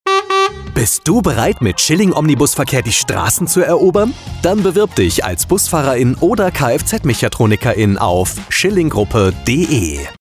Bekannt aus den ExtraTipps auf Deinem Lieblingssender Radio Köln!
Funkspot_Schilling-Omnibusverkehr-GmbH-10-Sek.mp3